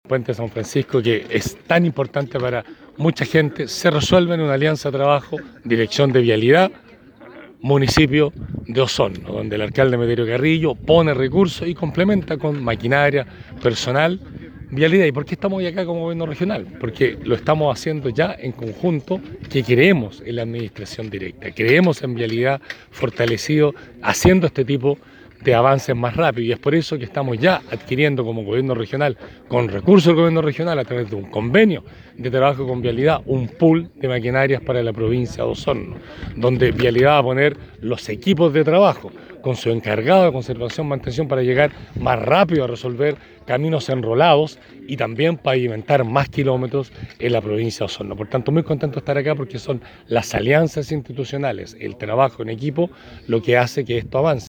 Durante el pasado fin de semana se realizó la ceremonia de inauguración de la reposición del Puente San Francisco, un anhelado proyecto por parte de los vecinos del sector distante a 8 kilómetros de Osorno por la Ruta 5 hacia el norte.
Por último el Gobernador Regional Patricio Vallespin, enfatizó en que estos trabajos conjuntos que se realizan permiten avanzar en dar mejores condiciones de vida a los vecinos de Los Lagos.